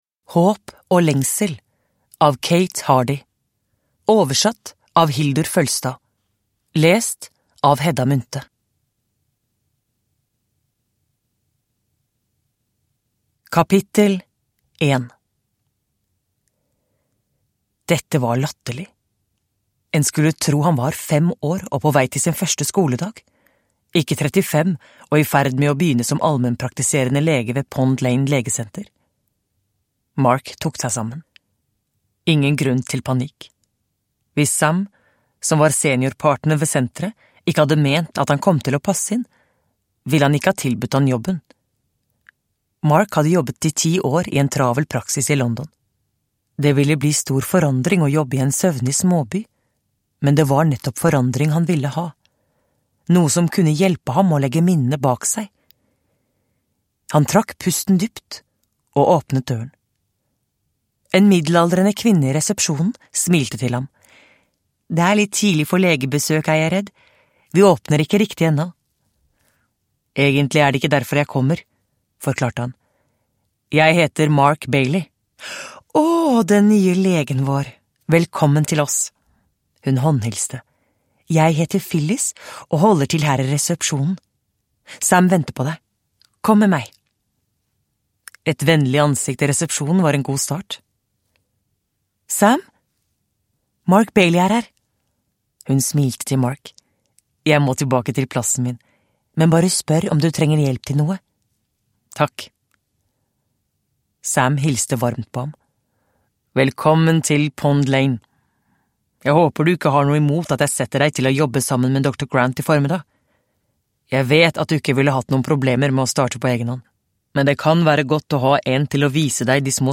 Håp og lengsel – Ljudbok – Laddas ner